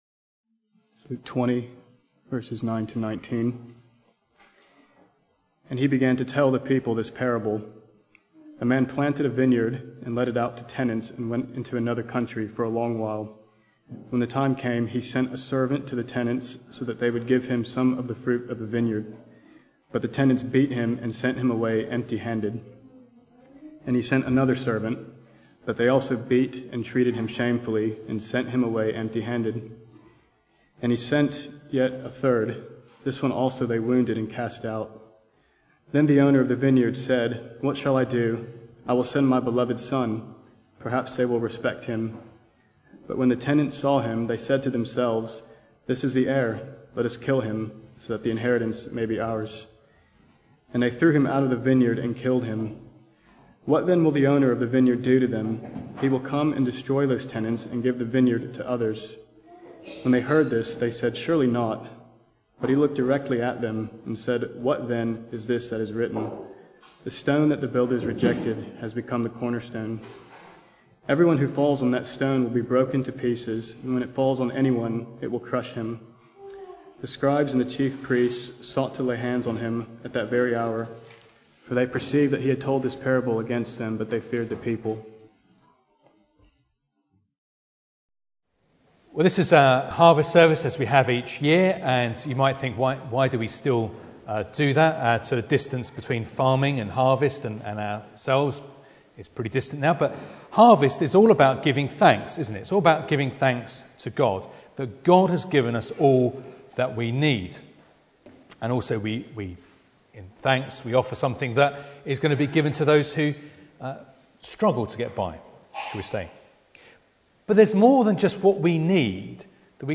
Luke 20:9-19 Service Type: Sunday Morning Bible Text